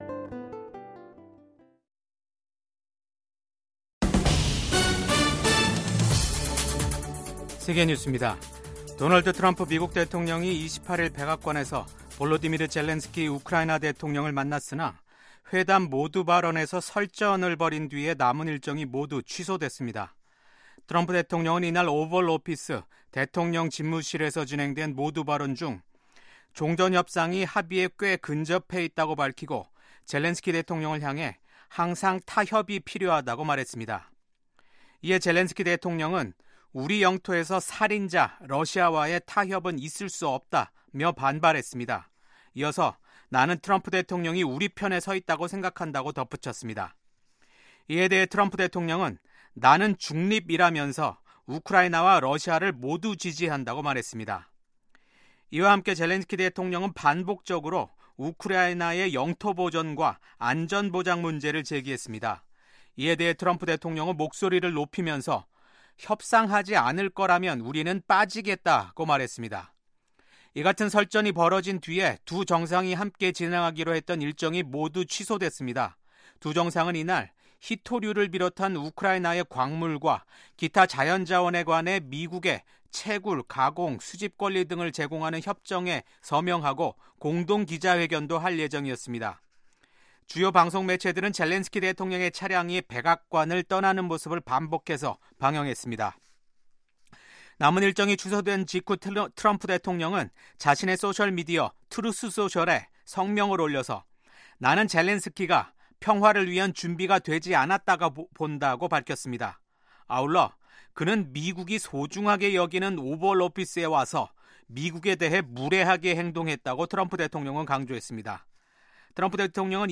VOA 한국어 아침 뉴스 프로그램 '워싱턴 뉴스 광장'입니다. 북한이 김정은 국무위원장 참관 아래 서해상으로 전략순항미사일 발사 훈련을 한 가운데, 미한 연합훈련을 앞둔 압박 메시지를 담고 있다는 분석이 나옵니다. 미국 정부가 사상 최대 규모인 15억 달러 상당의 암호화폐를 탈취한 ‘바이비트’ 해킹 사건이 북한 해킹 조직의 소행임을 공식적으로 확인했습니다.